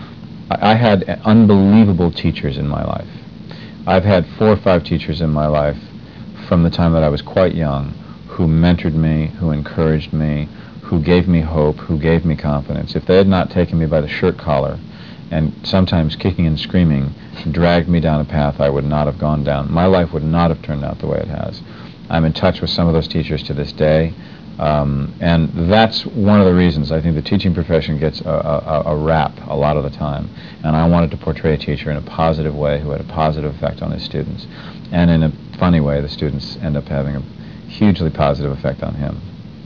Kevin talks about teachers, and what it was like to portray one.